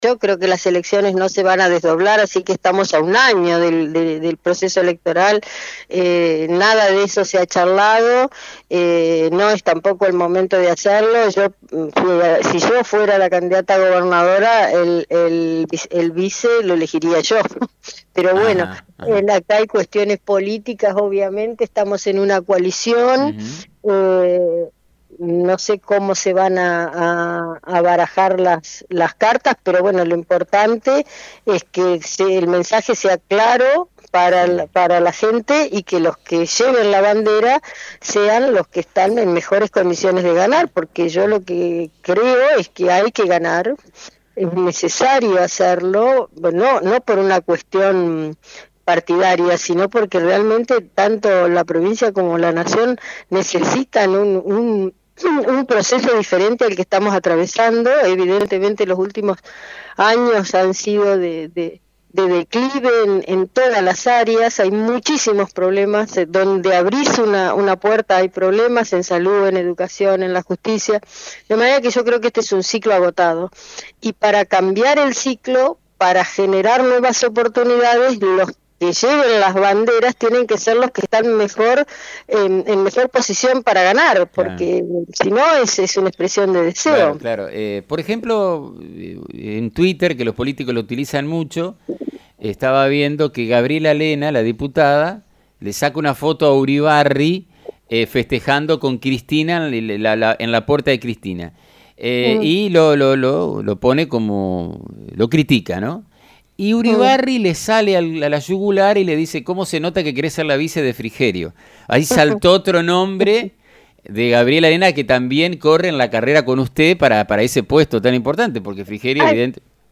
En diálogo con FM 90.3, la diputada provincial brindó su opinión sobre los candidatos a las próximas elecciones y aseguró que le gustaría participar de la lista de la UCR si es que los votantes acompañarían esa decisión.